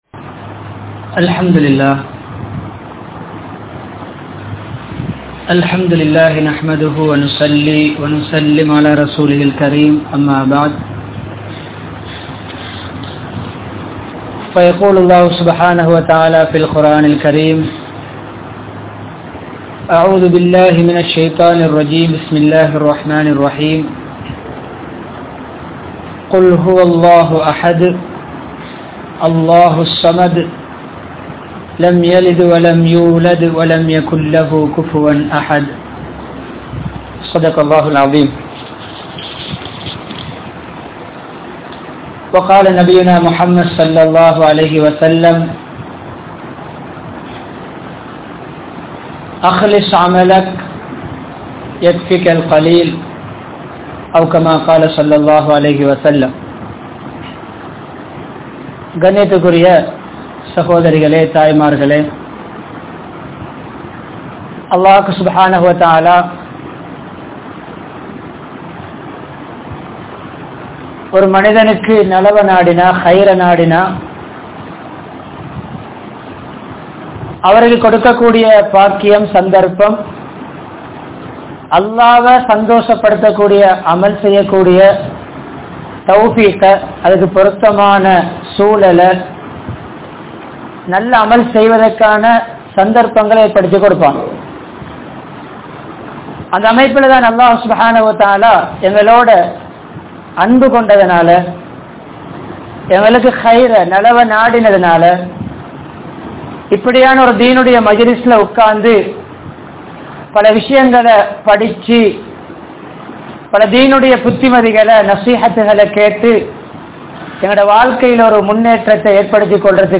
Ihlaas Illaatha Amalhal Alium (இஹ்லாஸ் இல்லாத அமல்கள் அழியும்) | Audio Bayans | All Ceylon Muslim Youth Community | Addalaichenai